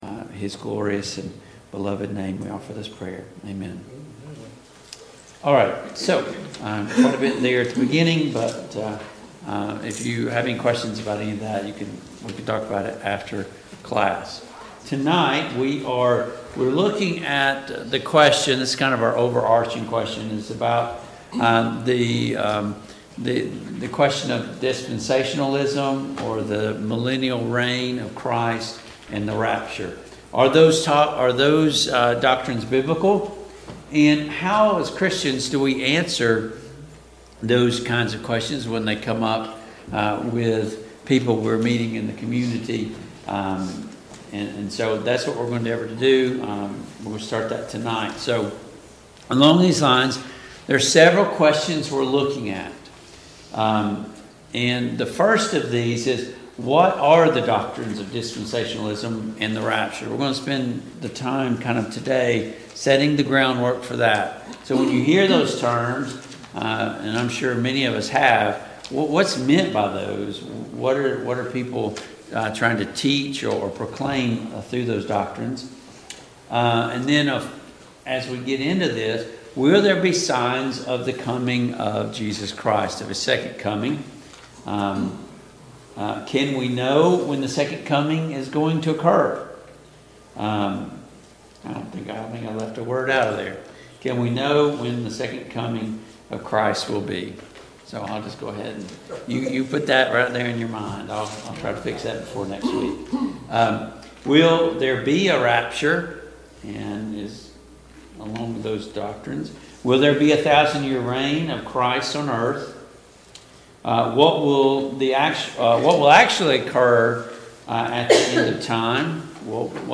Matthew 24:1-8 Service Type: Mid-Week Bible Study Download Files Notes Topics: The End of Time , The Second Coming of Jesus Christ « 3.